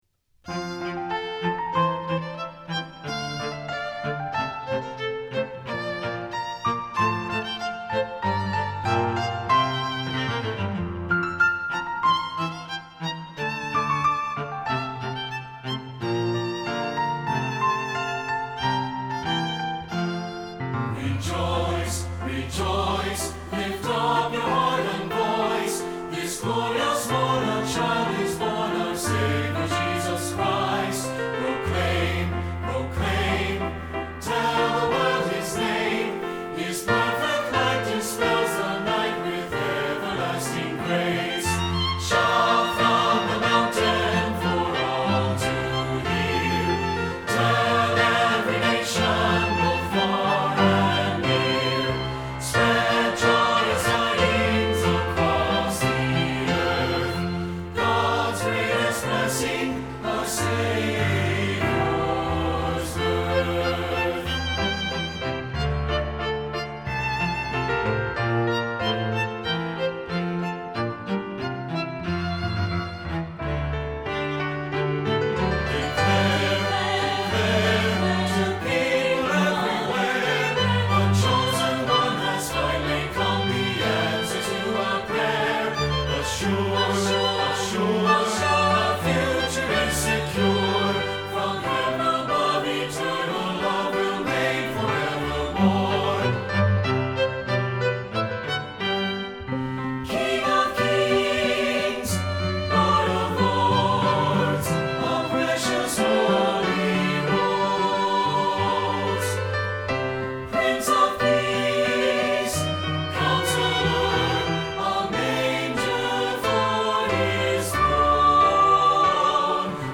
Choral Christmas/Hanukkah
SAB